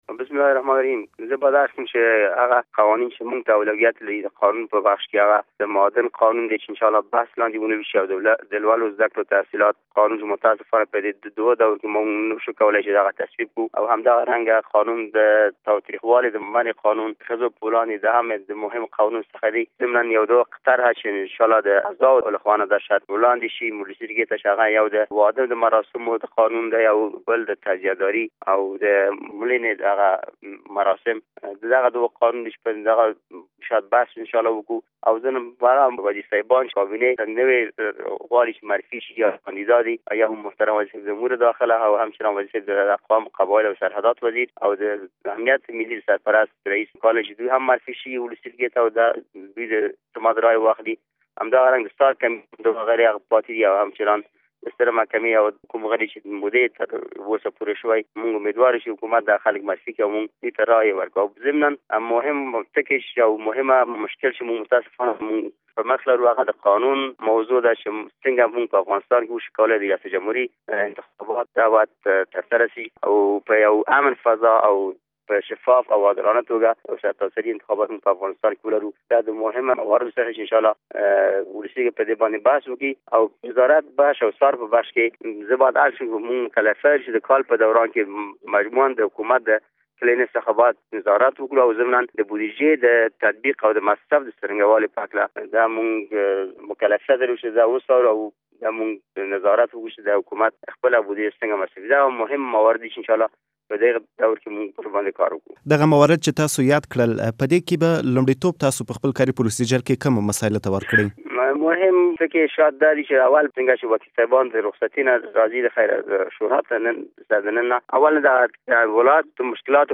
د ولسي جرګې له منشي عرفان الله عرفان سره مرکه